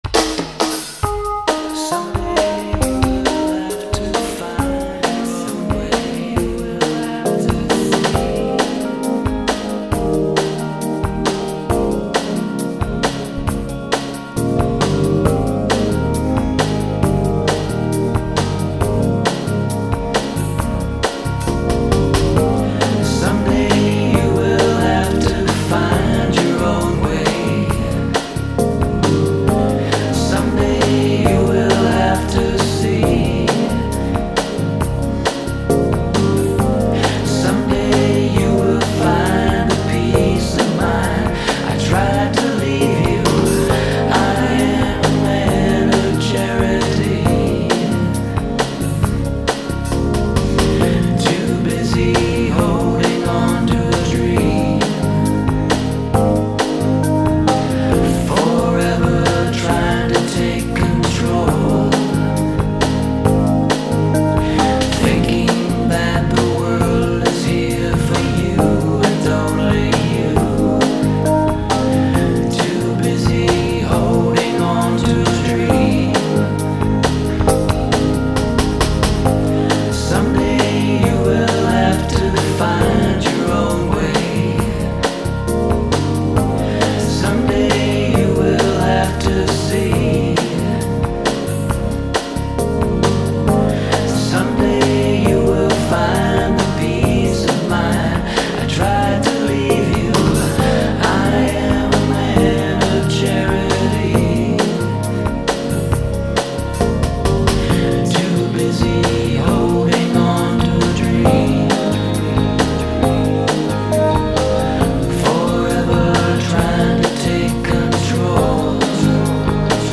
Recapturing AM radio in pop songs with lush arrangements.